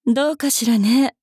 大人女性│女魔導師│リアクションボイス│商用利用可 フリーボイス素材 - freevoice4creators
疑う